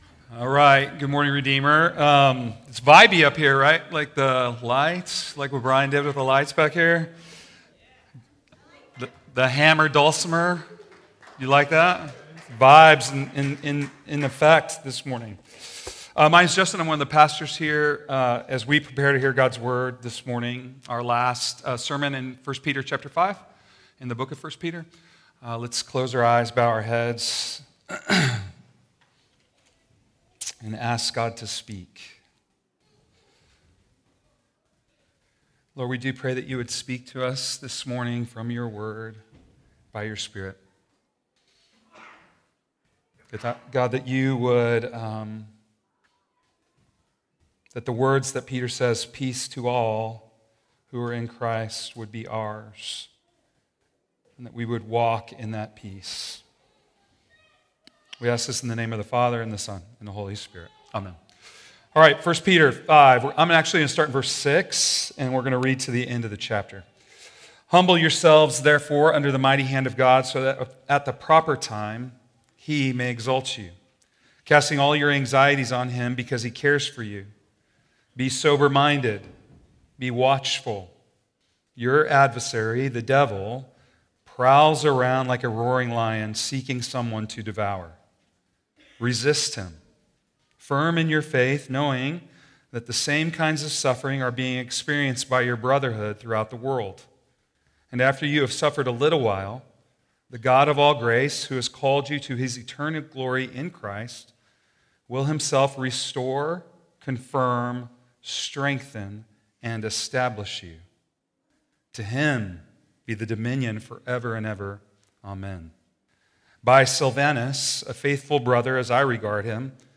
12.7 sermon - Made with Clipchamp.m4a